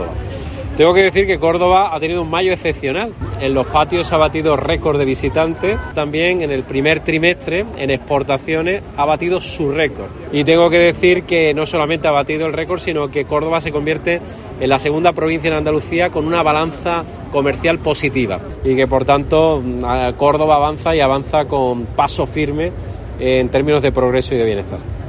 En una atención a los medios en la Feria de Córdoba, con la que culmina sus visitas a las citas festivas del mayo cordobés, Moreno ha resaltado que "en los patios se ha batido récord de visitantes, con 800.000, lo que ha supuesto que el 85% prácticamente de los hoteles hayan estado ocupados", y con ello "una inyección económica para la restauración y la hostelería", así como "una fuerte inyección económica para la provincia".